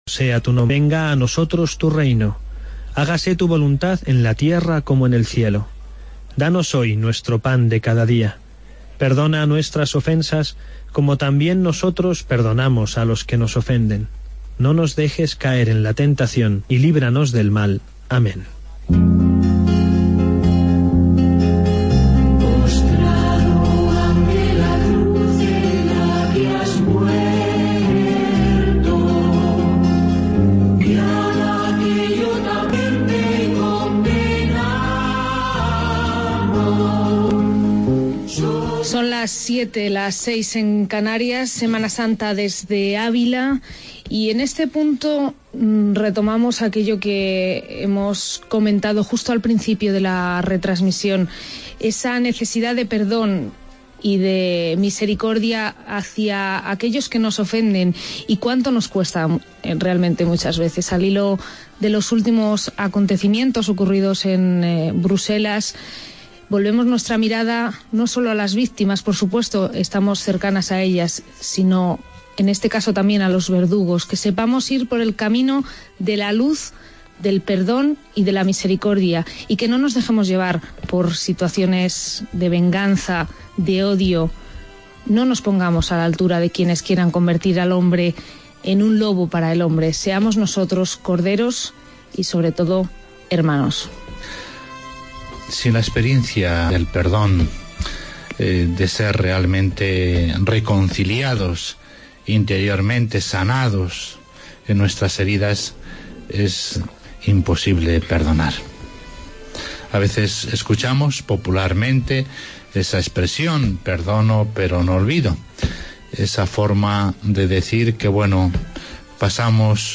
Redacción digital Madrid - Publicado el 25 mar 2016, 08:14 - Actualizado 18 mar 2023, 11:39 1 min lectura Descargar Facebook Twitter Whatsapp Telegram Enviar por email Copiar enlace Vía Crucis de Penitencia desde Avila